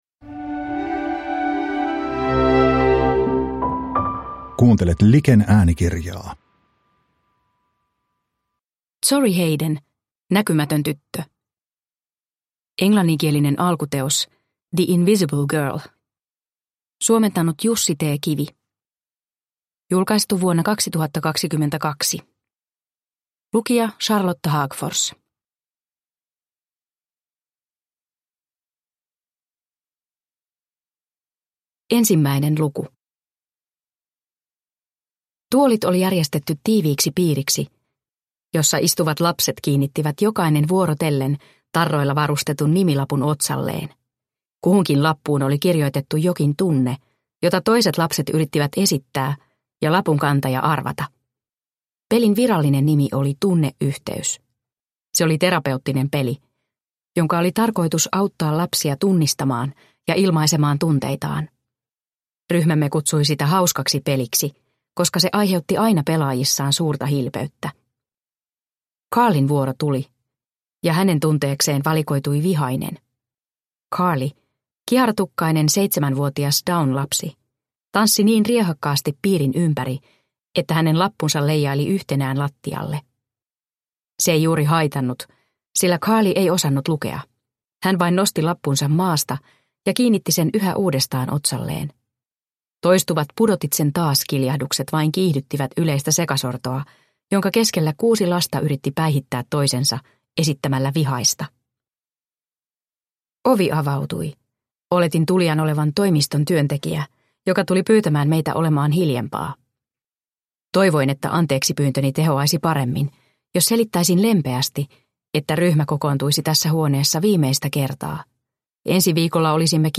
Näkymätön tyttö – Ljudbok – Laddas ner